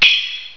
Ting3
TING3.WAV